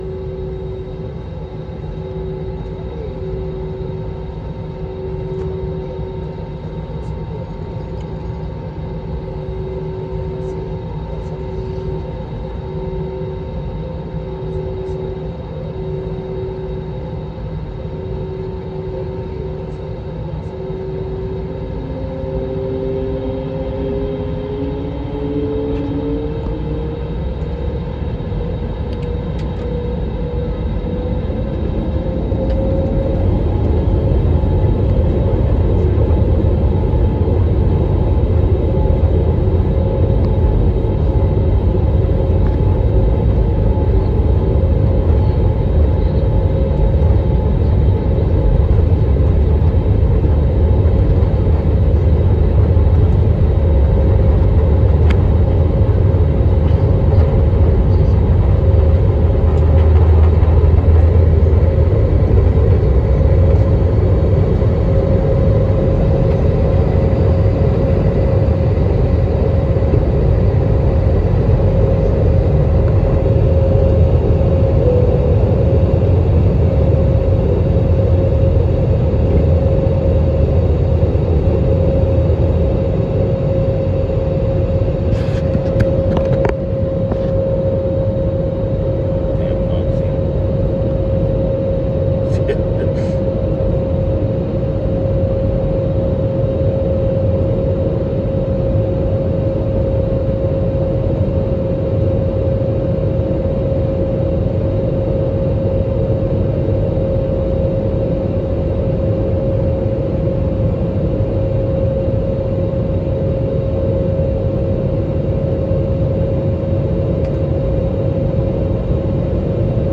First Five Minutes Of Taking Off From BAQ To Fll